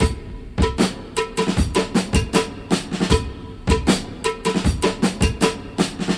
• 78 Bpm HQ Drum Groove C Key.wav
Free drum loop - kick tuned to the C note. Loudest frequency: 1603Hz
78-bpm-hq-drum-groove-c-key-ghz.wav